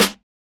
kits/Cardiak/Snares/CardiakSnare5wav.wav at main
CardiakSnare5wav.wav